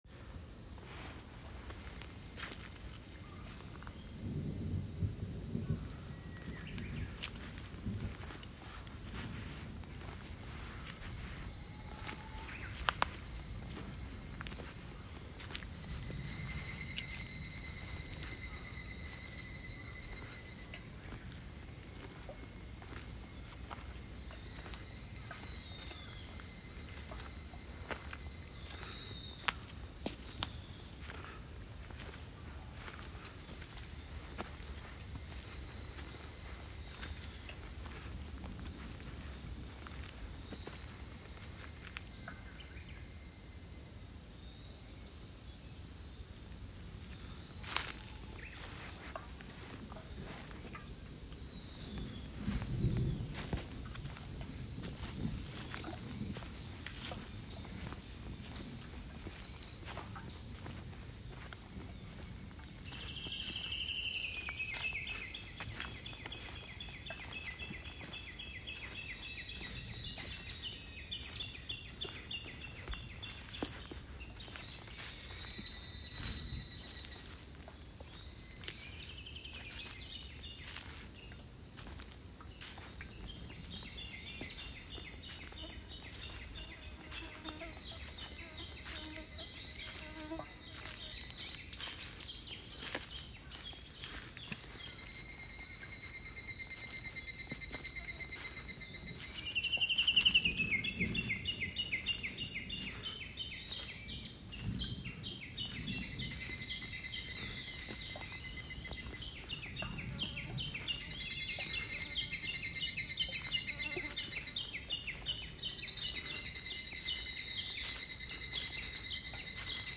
山梨県にある保健休養林「武田の杜」内「健康の森」で録音した自然環境音です。 ヒグラシやウグイスの声などが聴けます。
武田の杜の自然環境音（ヒグラシ、ウグイス）